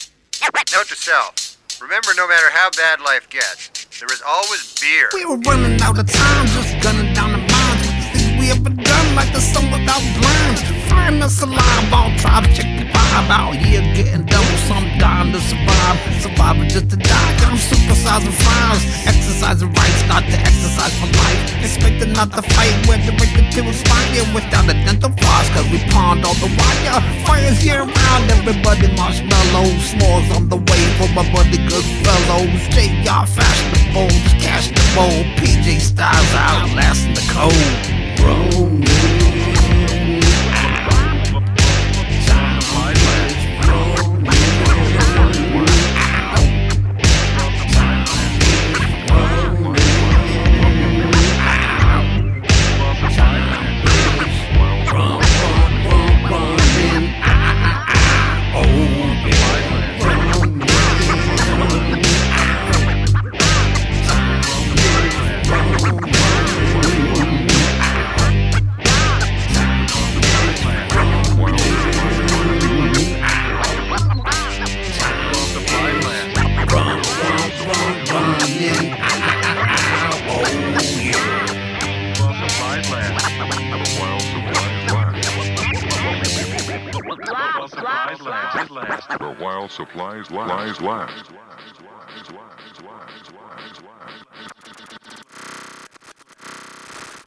Don’t expect polish.
It’s loud.